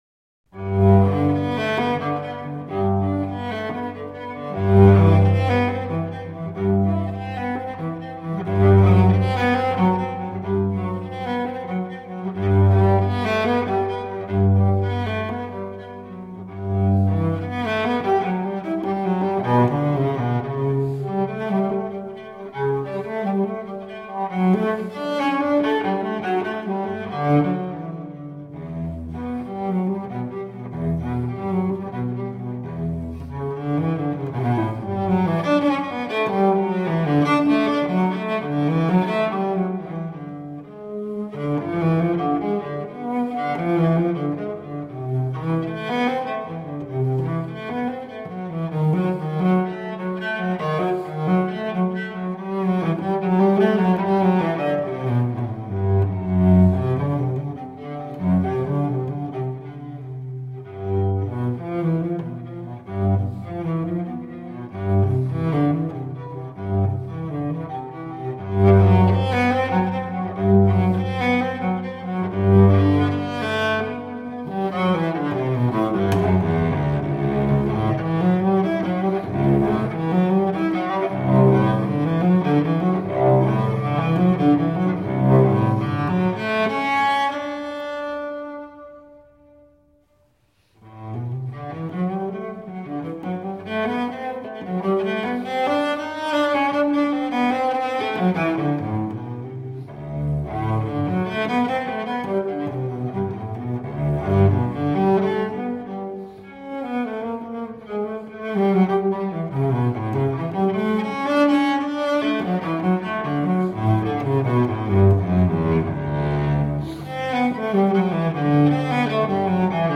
Cellist extraordinaire.